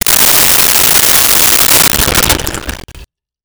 Sink Fill 04
Sink Fill 04.wav